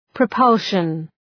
Προφορά
{prə’pʌlʃən}